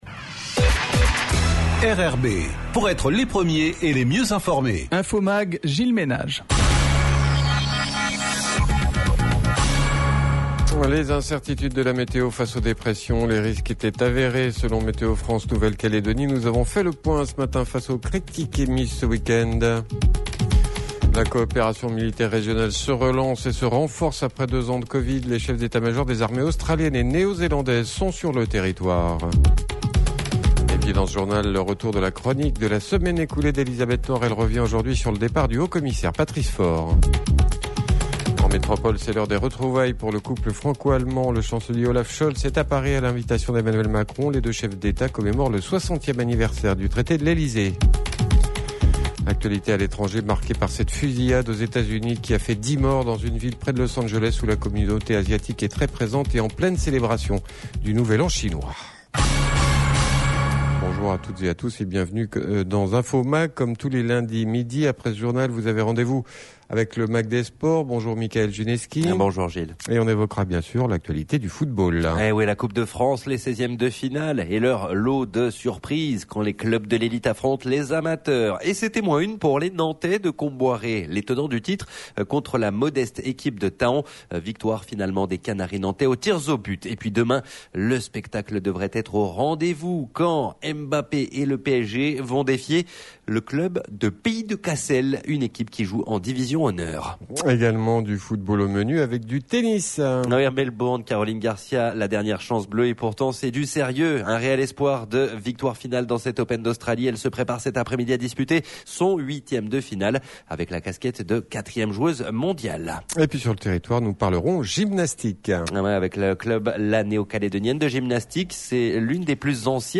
JOURNAL : LUNDI 23/01/23 (MIDI)